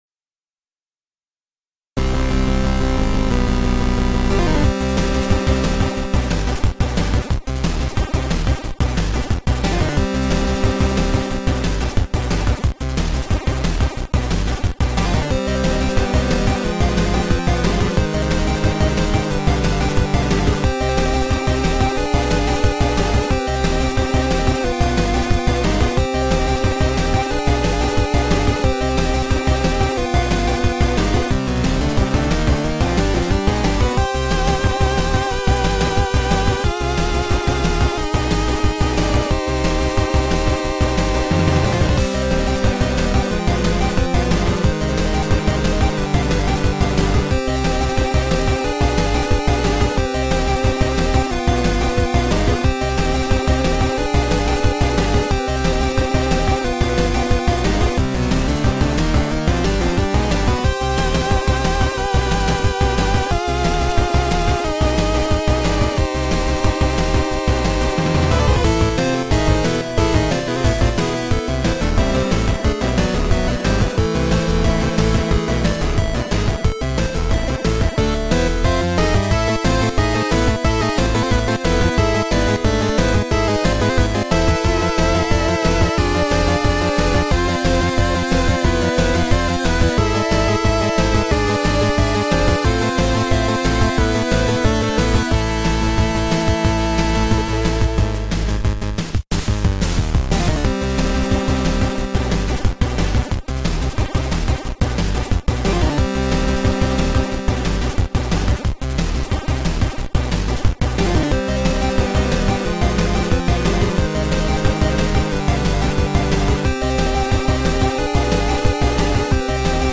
genre:8bit